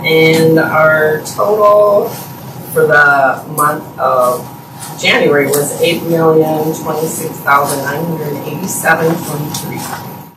Walworth County Auditor Kim Dills said the Auditor’s office balanced with the Treasurer’s office in January.